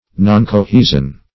Noncohesion \Non`co*he"sion\, n.